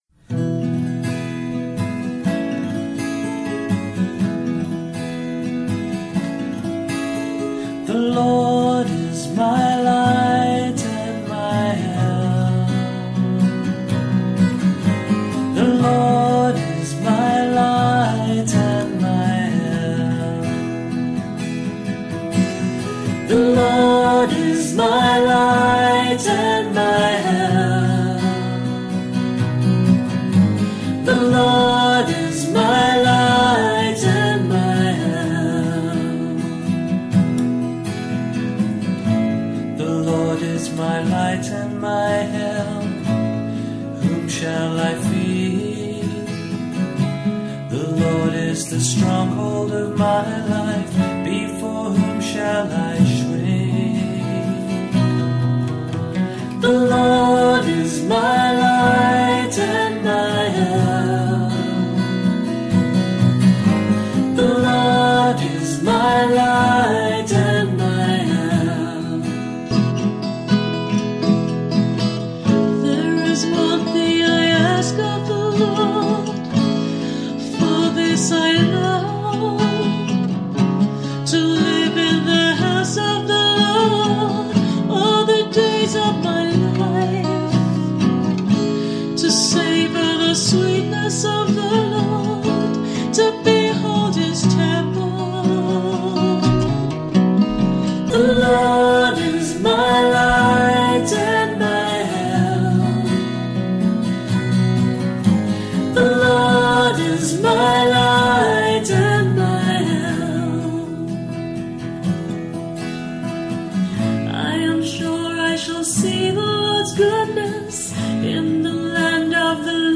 Responsorial Psalm for the 3rd Sunday in Ordinary Time